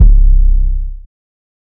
Heatens 808.wav